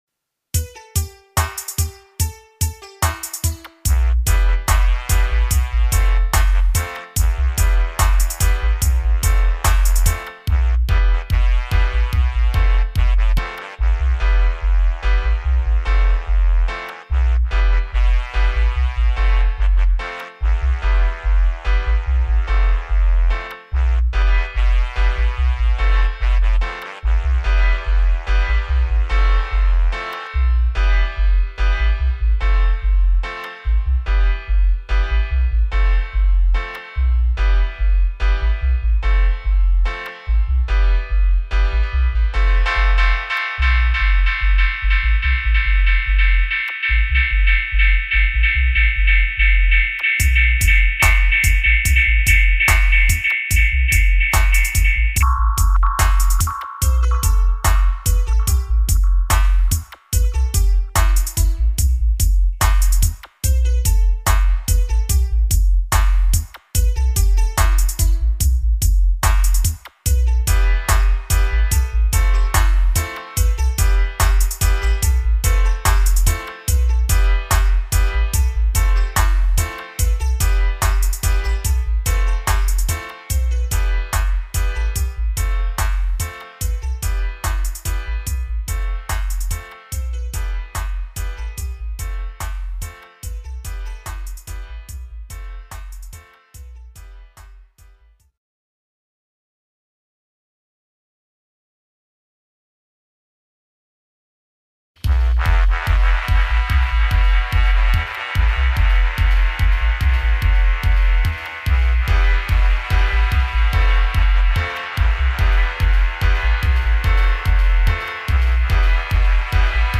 dubTest Mixes preview